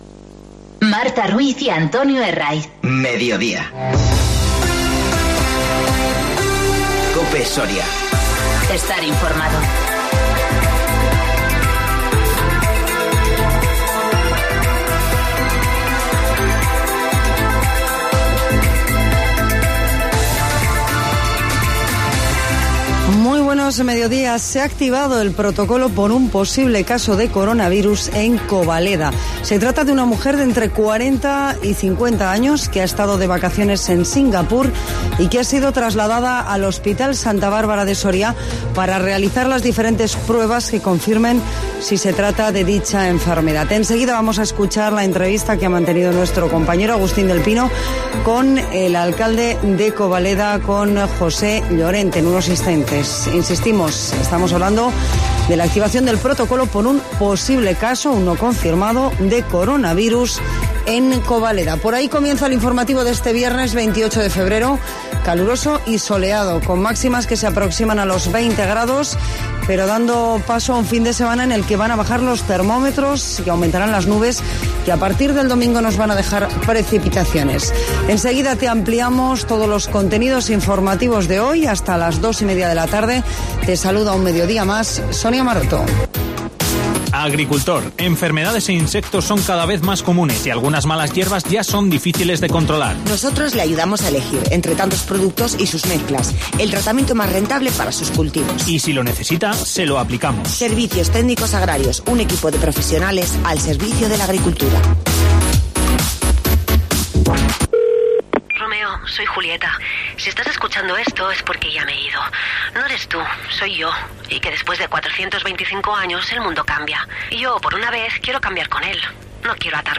Informativo 28-02-20